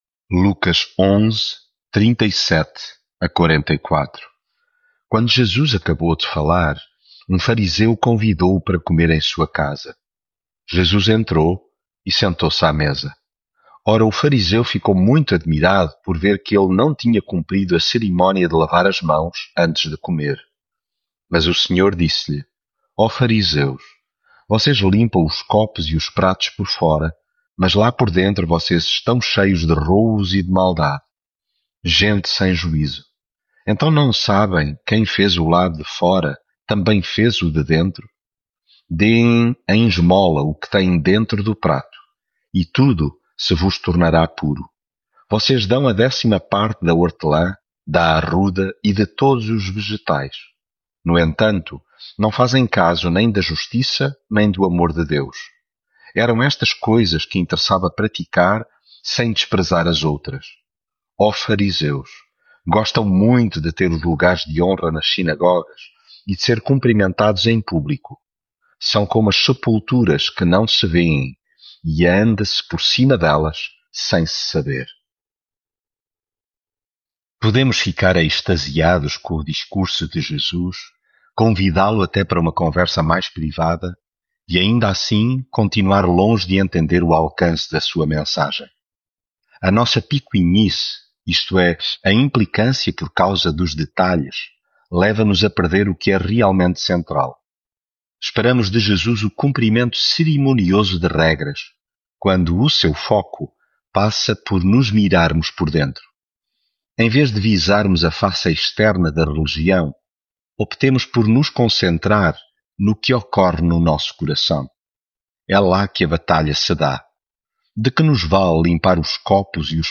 devocional Lucas leitura bíblica Ninguém acende uma lâmpada para escondê-la, mas coloca-a num candeeiro, para que aqueles que entrarem em casa vejam a luz.